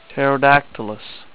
Pronunciation Key
ter-o-DAK-til-us